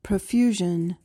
PRONUNCIATION:
(pruh-FYOO-zhuhn)